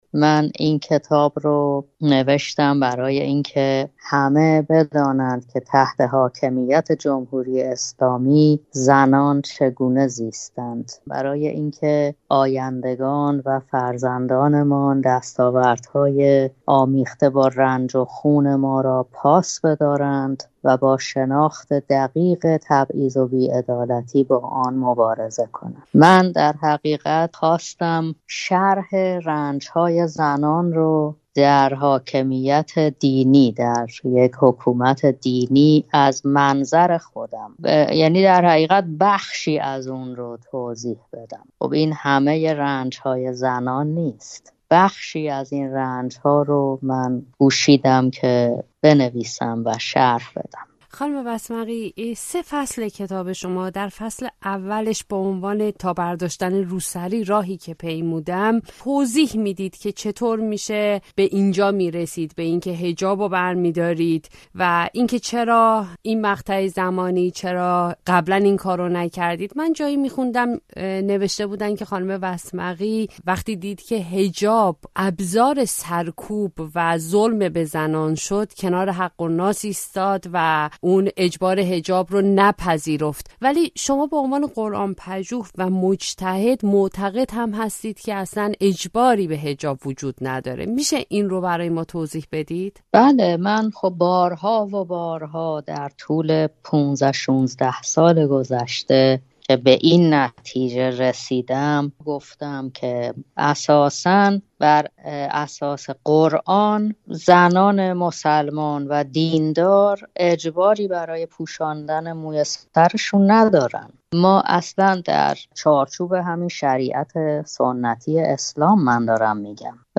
گفت‌وگو با صدیقه وسمقی؛ «چرا علیه حجاب شوریدم»